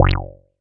Wet_Bass_F#2.wav